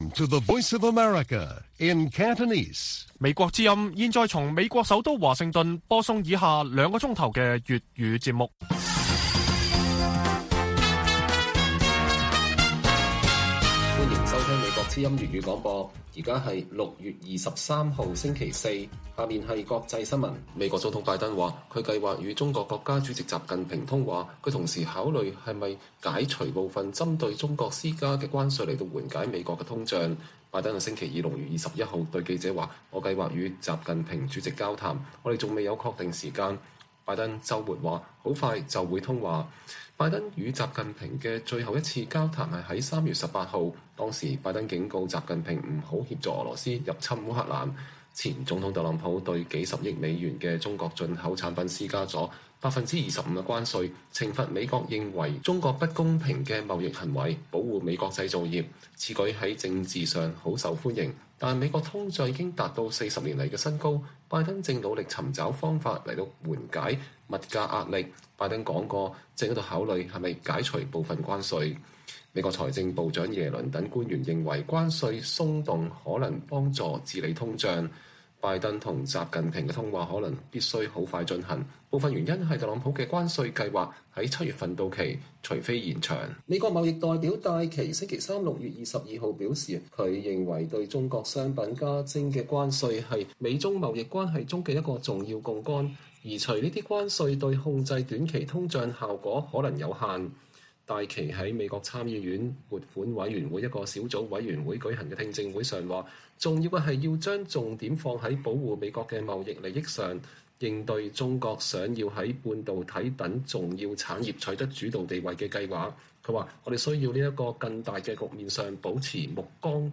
粵語新聞 晚上9-10點: 拜登說計劃與習近平通話